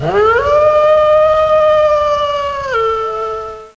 wolf.mp3